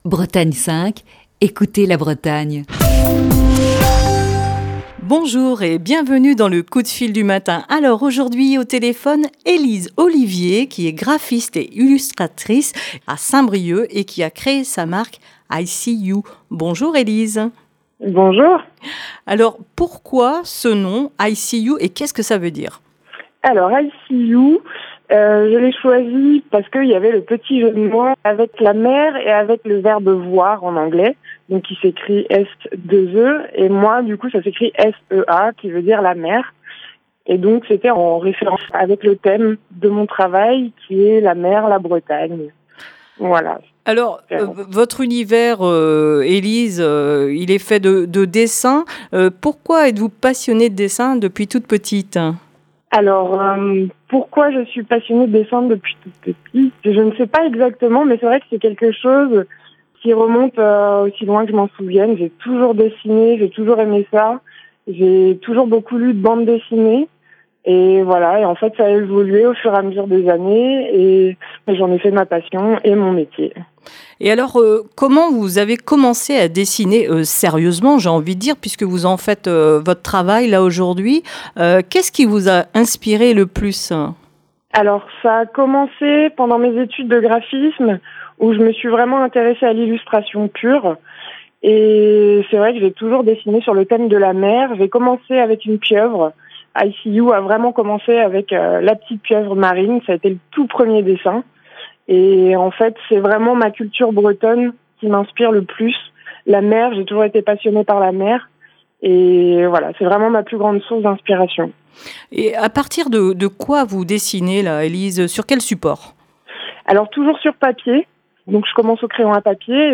Émission du 14 mai 2020.